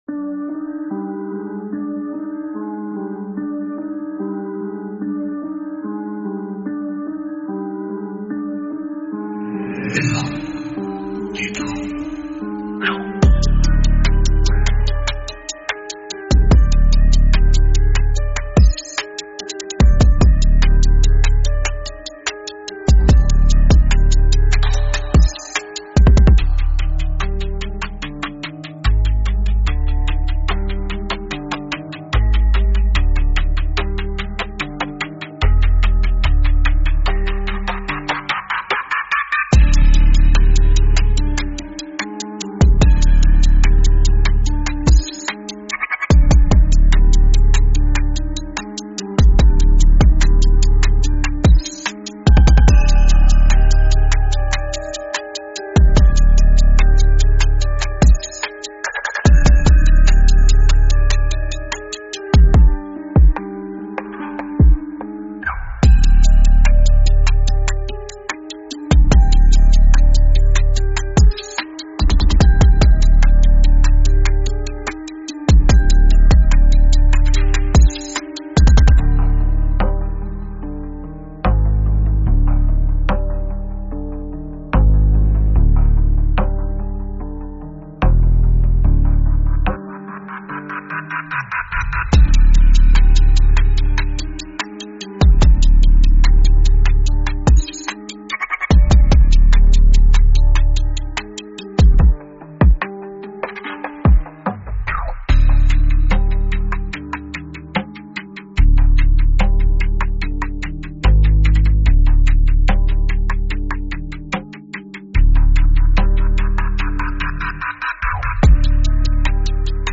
Instrumental - Real Liberty Media DOT xyz